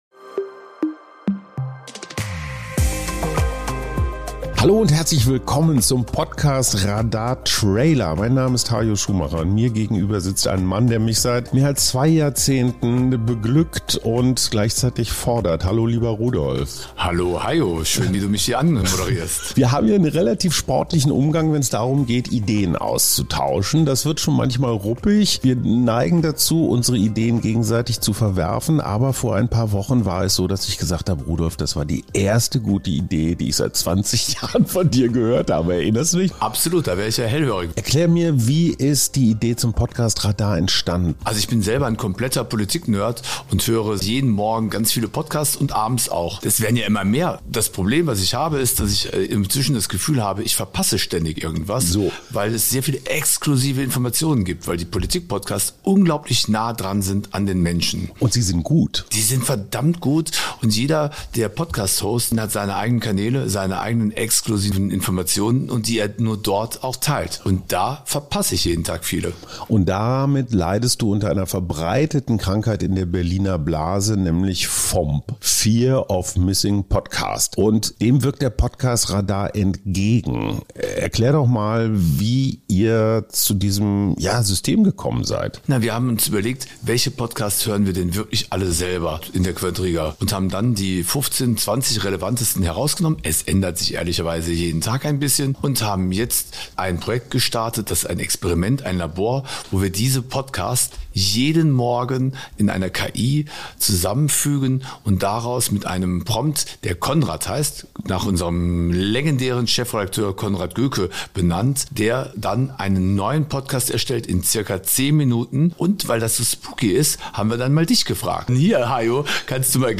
vorgetragen von den KI-Stimmen Ihres Vertrauens.